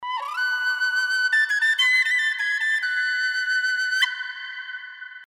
без слов
фолк
Флейта
дудка
Чудный звук сопилки